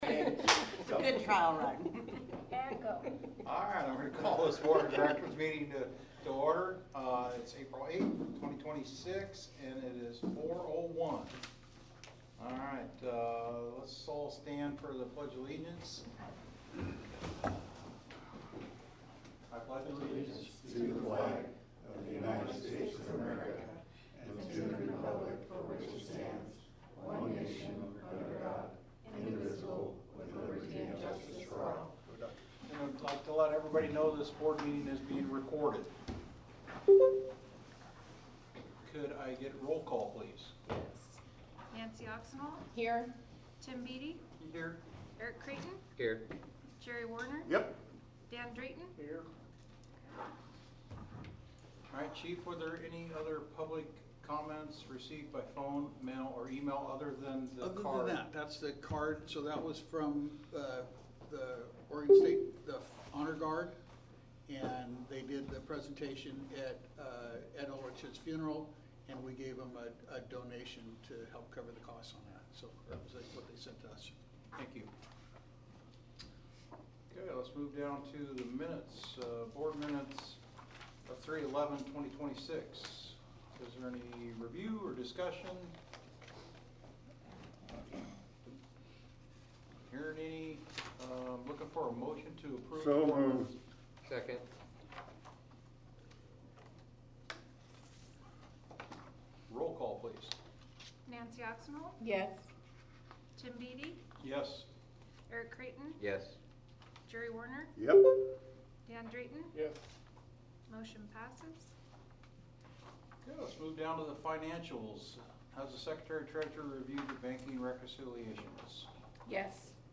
Board Meeting
Regular+Board+Meeting+4-8-26.MP3